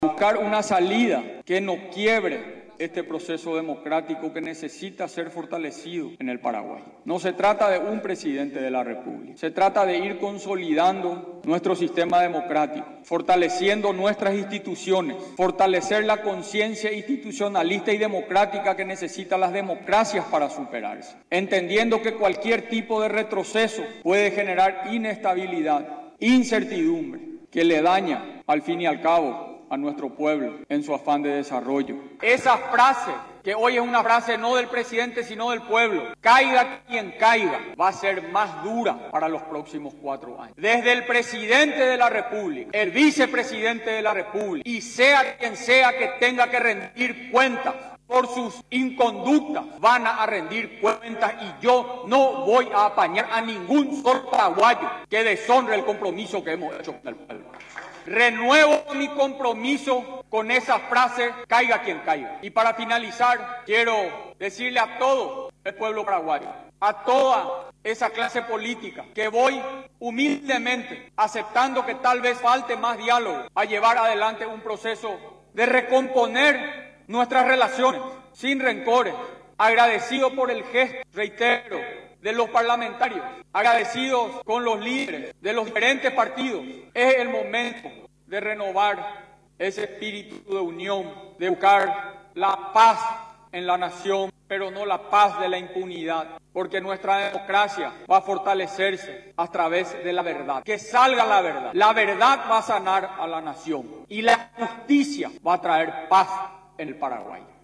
El mandatario paraguayo, durante el mensaje de este jueves a la ciudadanía, realizado en Palacio de Gobierno, sostuvo que el proceso democrático debe ser fortalecido en nuestro país.